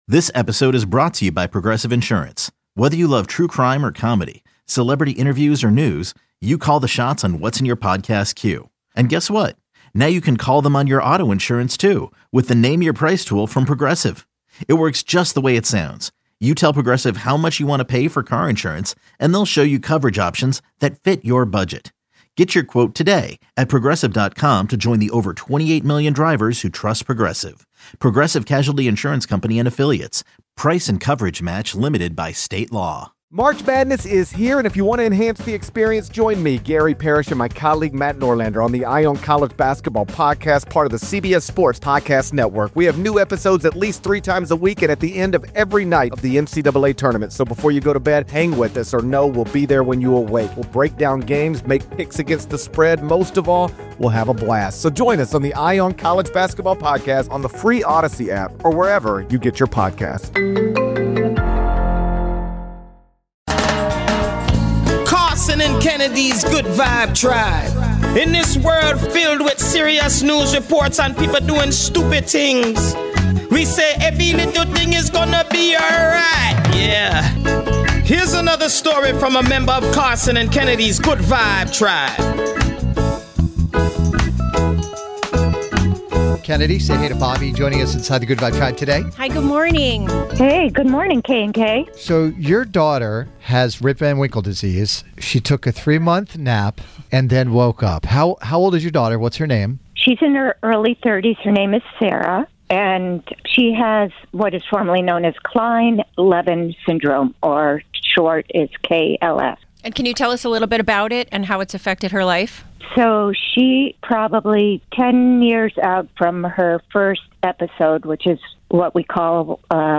The show is fast paced and will have you laughing until it hurts one minute and then wiping tears away from your eyes the next.